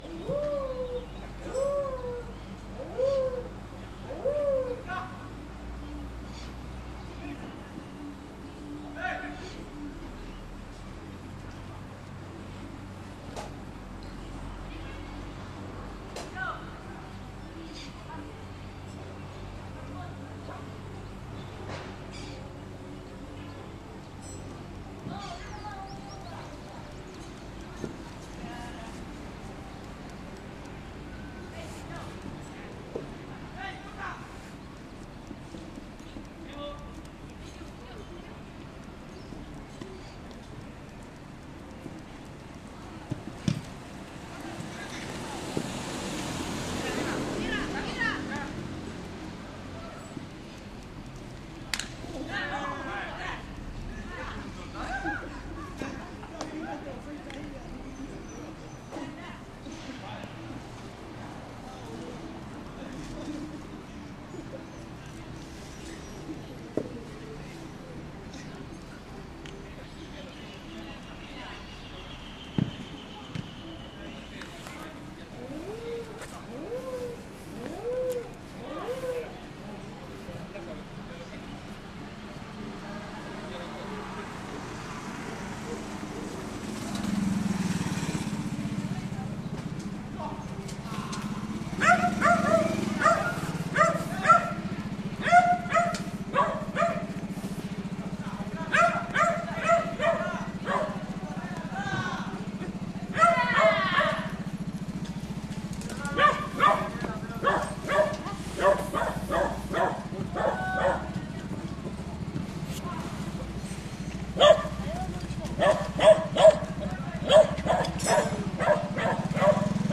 Paisajes Sonoros de Rosario
psr-colombia-250-bis.mp3